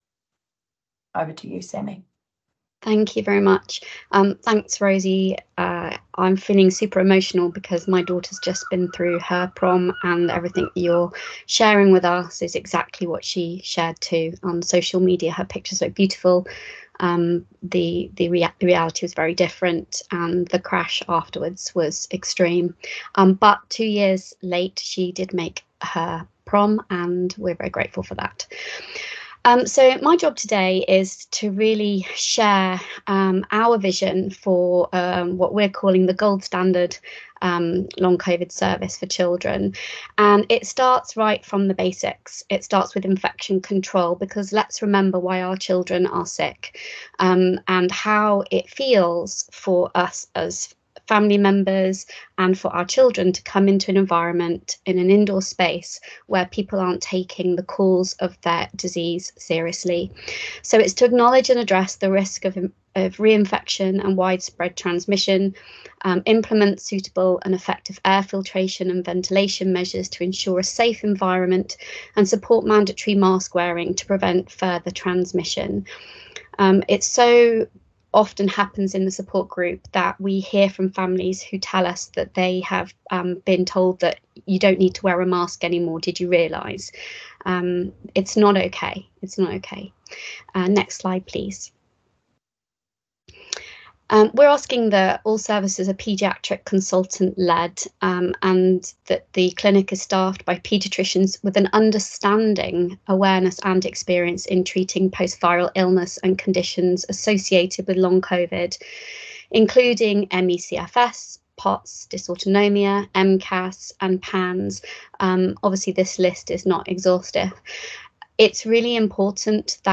Listen to the audio of the presentation here it will open in another window.